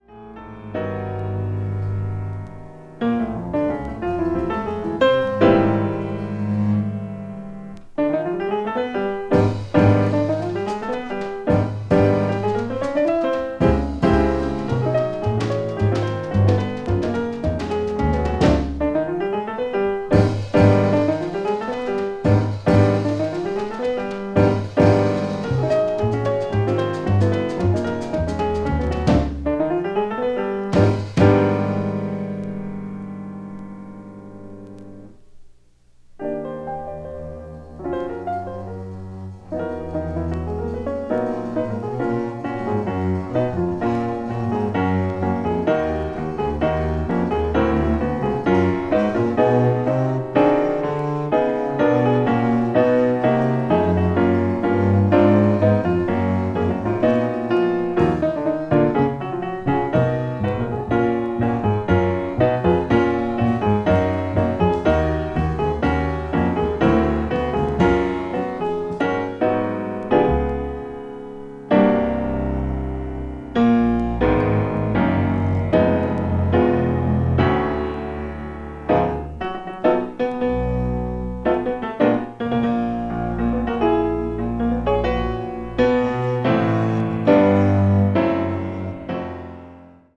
bop   modern jazz   piano trio